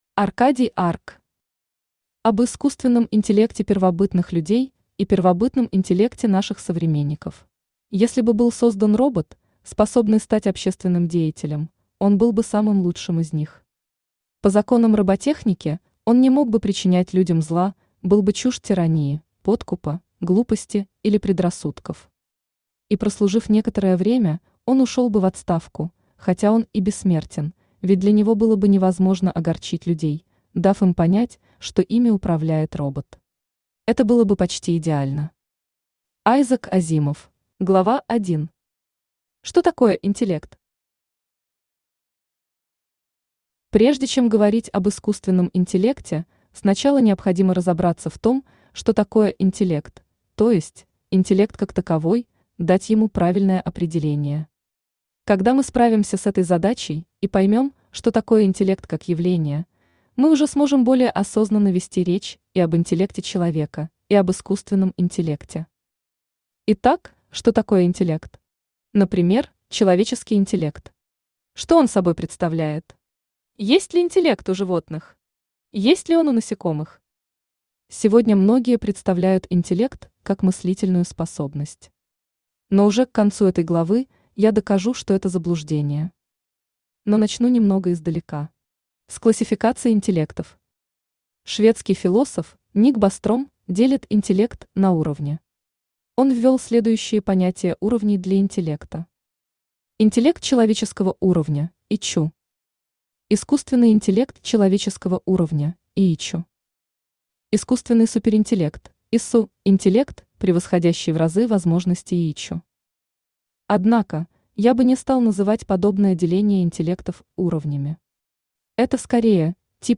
Аудиокнига Об искусственном интеллекте первобытных людей и первобытном интеллекте наших современников | Библиотека аудиокниг
Aудиокнига Об искусственном интеллекте первобытных людей и первобытном интеллекте наших современников Автор Аркадий Арк Читает аудиокнигу Авточтец ЛитРес.